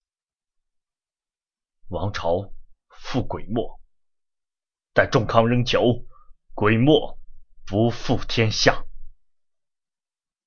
杀气男声